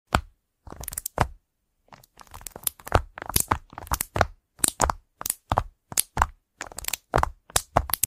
Bubble Wrap Keyboard ASMR 😩😩😩😩😩 sound effects free download